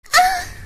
Yamete kudasai sound button
Efeito sonoro WOW Yamete kudasai Remix
Categoria: Sons de Anime